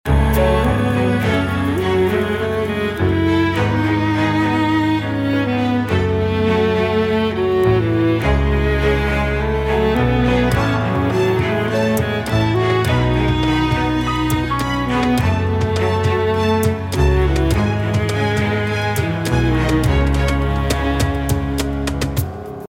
آهنگ موبایل ملایم و بی کلام